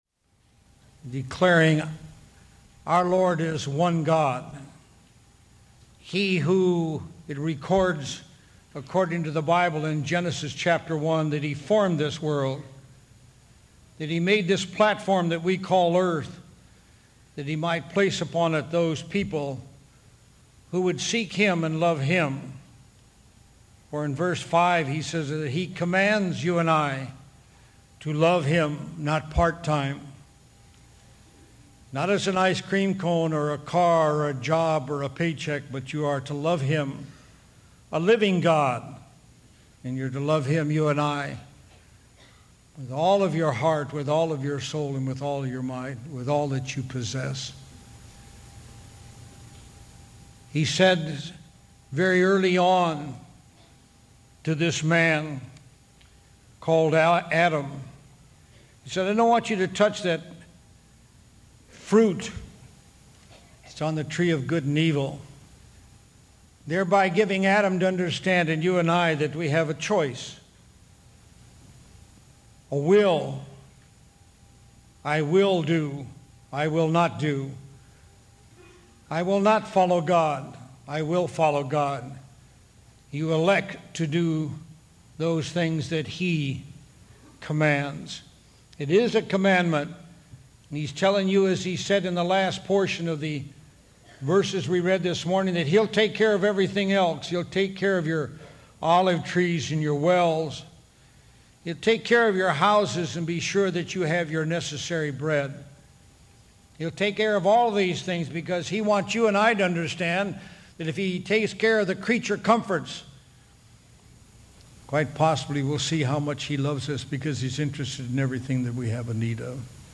God's Command is Love download sermon mp3 download sermon notes Welcome to Calvary Chapel Knoxville!